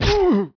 1 channel
gen_die2.wav